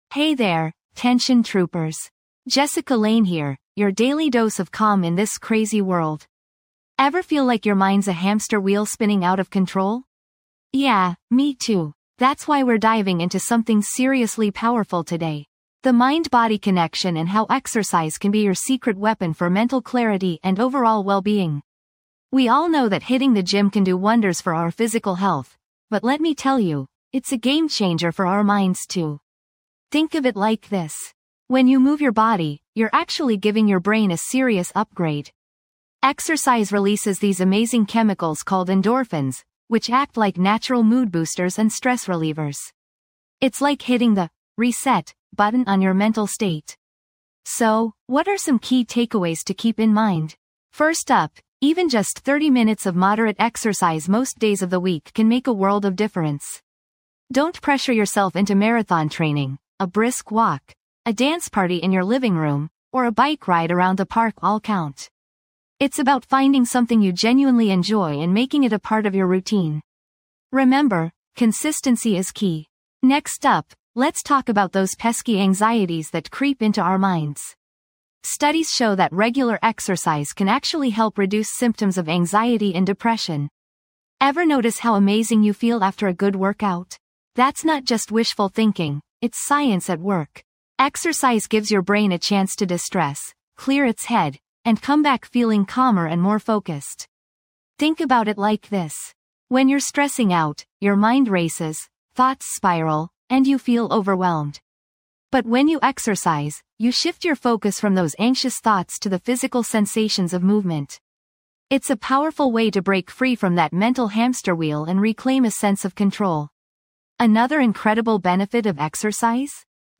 Through guided meditations, mindfulness exercises, and soothing soundscapes, we help you release tension, quiet your racing thoughts, and cultivate a sense of deep relaxation.